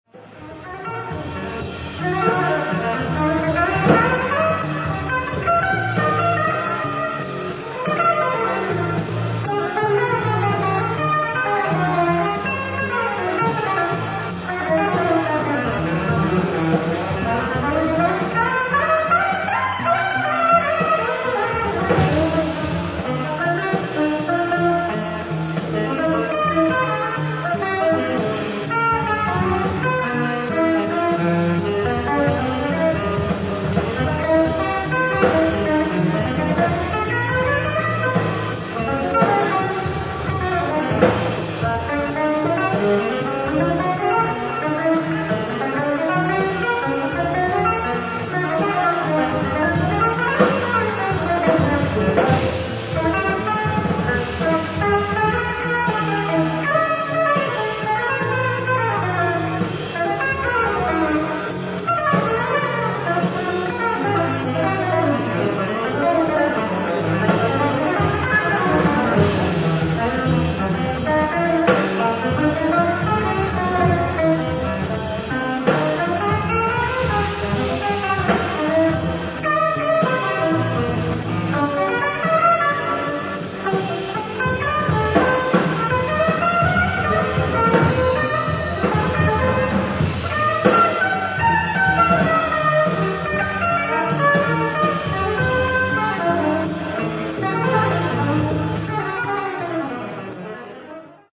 ディスク１＆２：ライブ・アット・パレスシアター、ニューヘブン、コネチカット 05/10/1986
※試聴用に実際より音質を落としています。